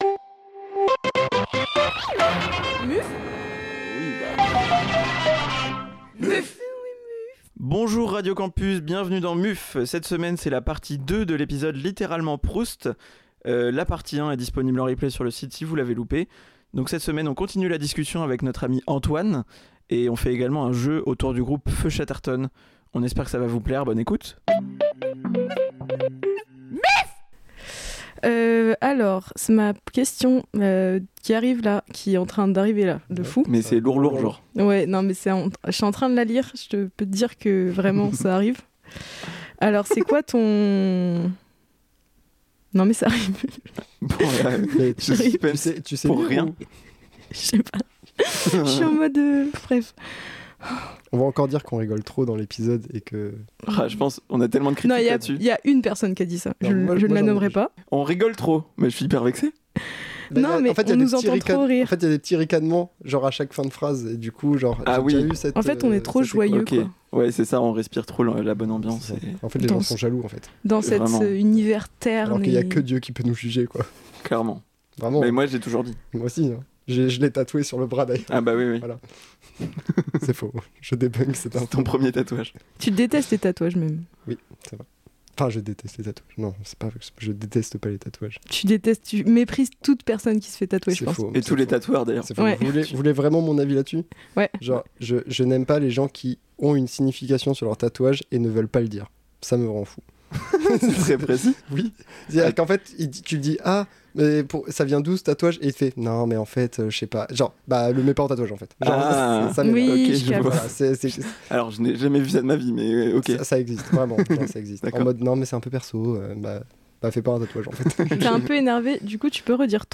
Cette semaine, la suite de la discussion